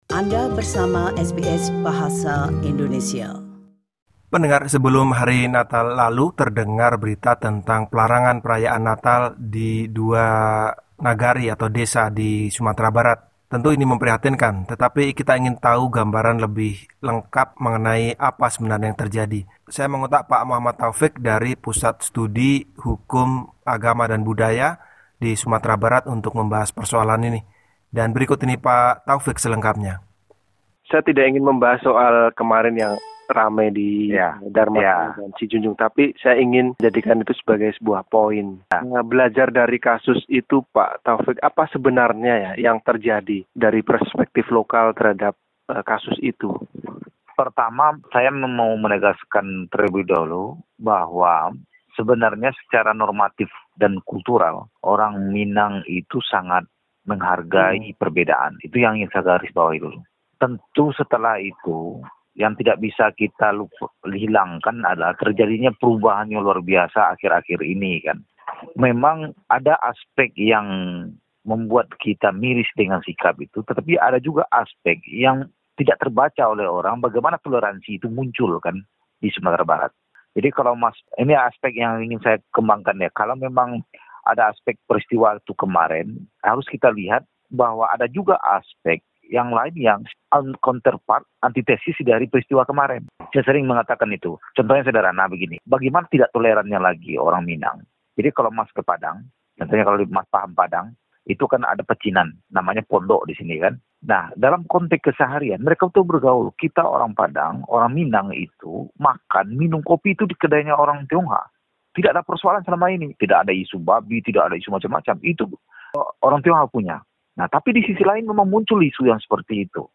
Berikut wawancara